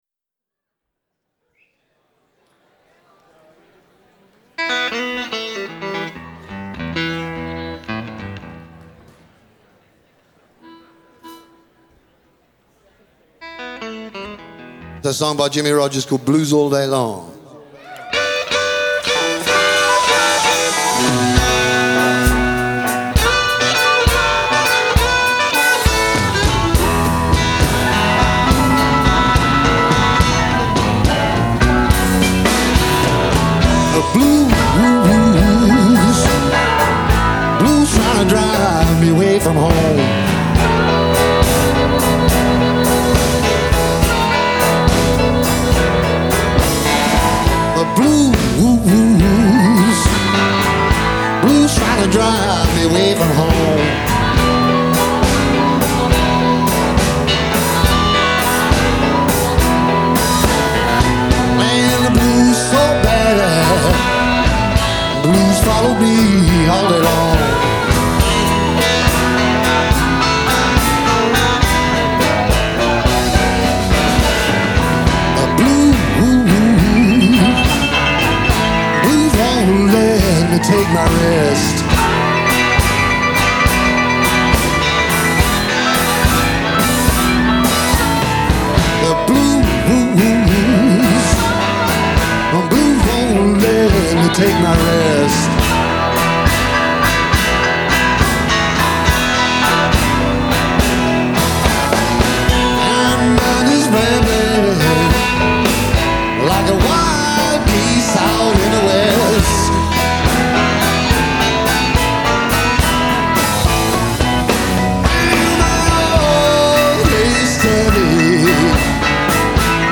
Genre : Blues ,Rock
Live at the Fillmore, San Francisco, 1994